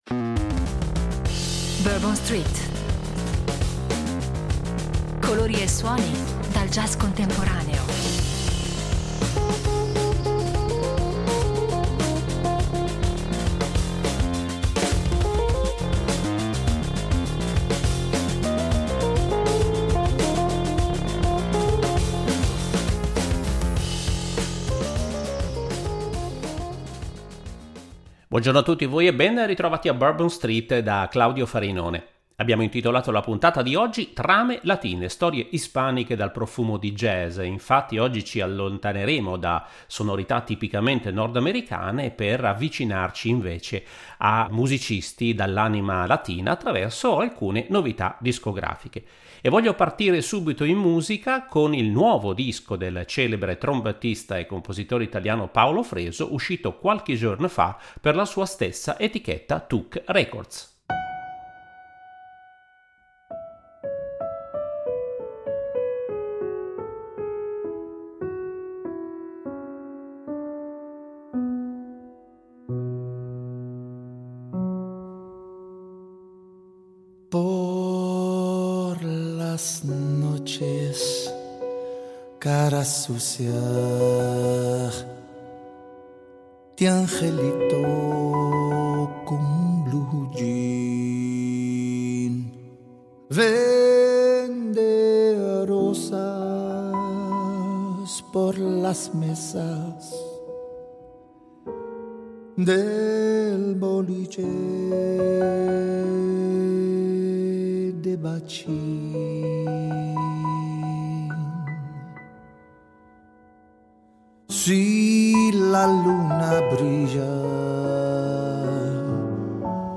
Storie ispaniche dal profumo di jazz
La puntata odierna di “ Bourbon Street ” si allontana dalle sonorità nordamericane per profumarsi di anime latine attraverso alcune novità discografiche.